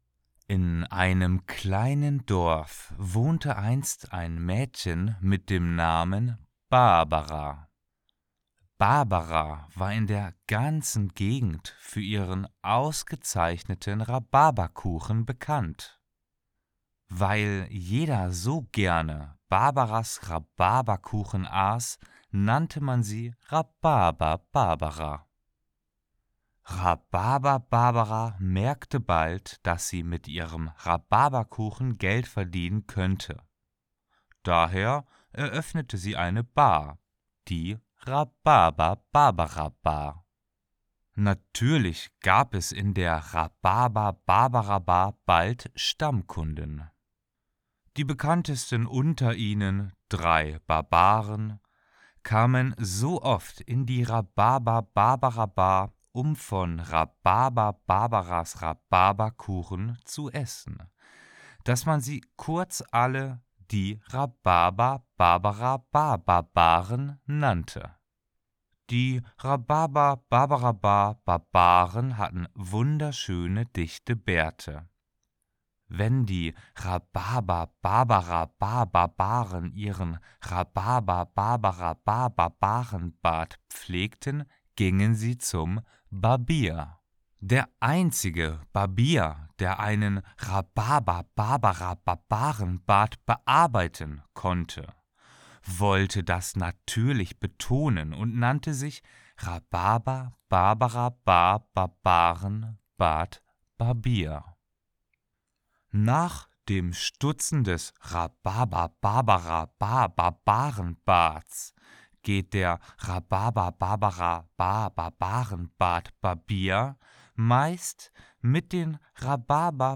Nun habe ich mit meinem neuen Mic endlich mal die Gelegenheit beim Schopfe gepackt.